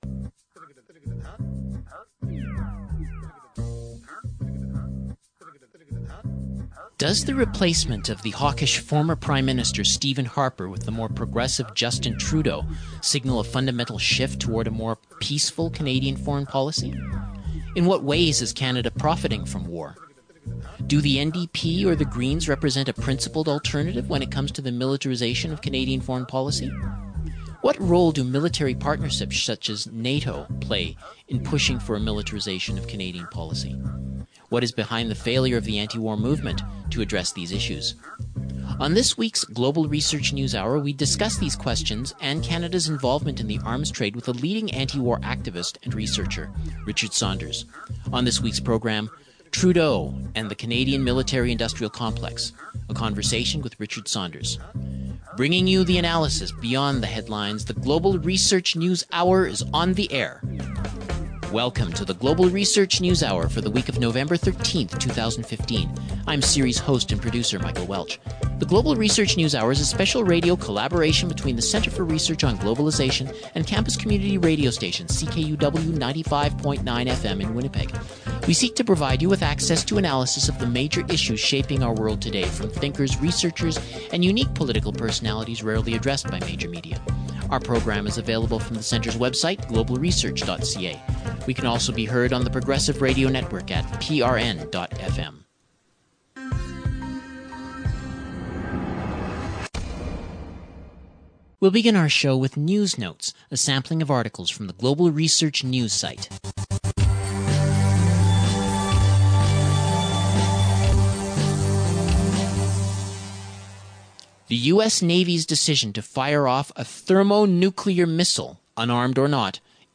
File Information Listen (h:mm:ss) 0:59:00 GRNH| Trudeau and the Canadian Military-Industrial Complex Download (15) GRNH_Nov_13,2015_episode_121_session_mixdown.mp3 42,490k 0kbps Stereo Listen All